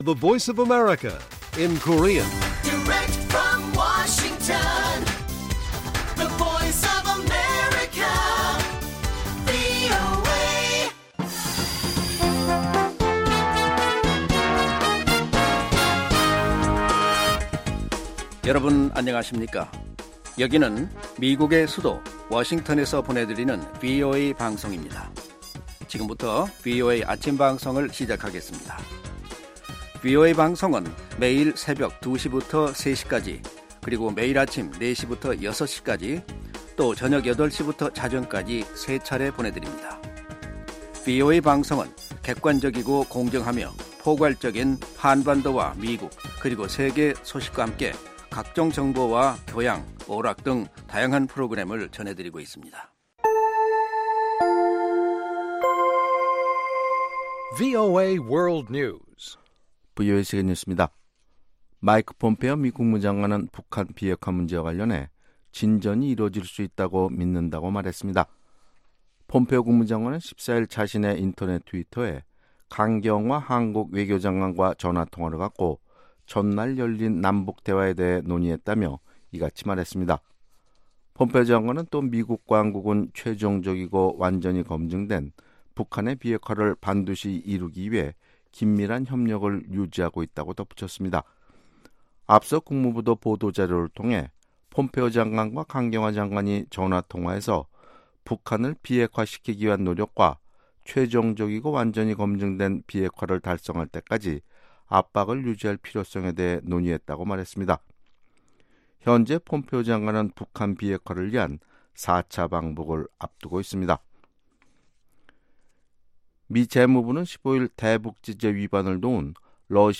세계 뉴스와 함께 미국의 모든 것을 소개하는 '생방송 여기는 워싱턴입니다', 2018년 8월 16일 아침 방송입니다. ‘지구촌 오늘’에서는 중국에서 VOA 취재진이 사법당국에 붙잡혔다 6 시간여 만에 풀려났다는 소식, ‘아메리카 나우’에서는 미국 내 4개주에서 프라이머리가 진행된 가운데 버몬트 주지사 민주당 후보 경선에 성전환가 당선돼 눈길을 끌고 있다는 이야기 전해드립니다. 타박타박 미국 여행'에서는 바람의 사람들이 사는 곳, 캔자스를 찾아갑니다.